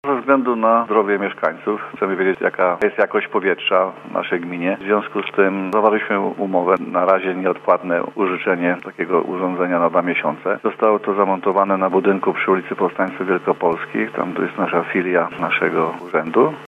Janusz Dudojć, burmistrz Lubska, mówi, że urządzenie pozwoli zadbać o zdrowie osób mieszkających w mieście, ale także jego okolicach: